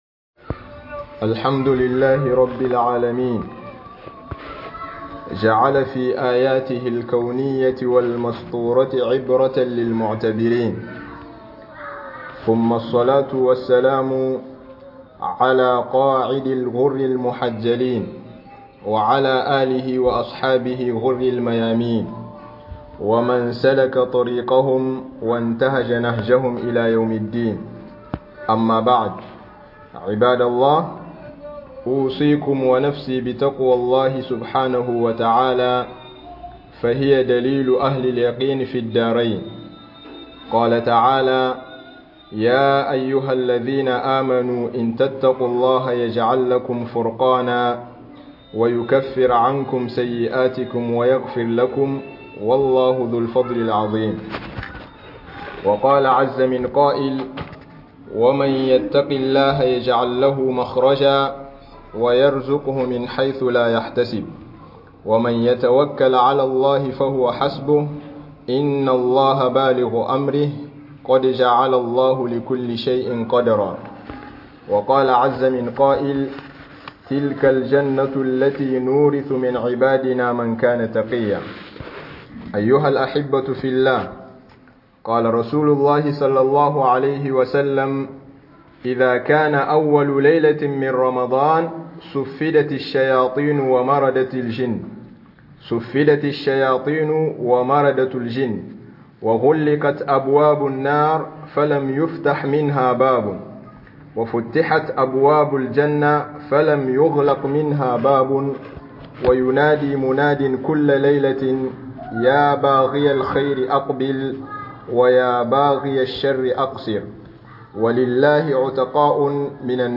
Hudubobi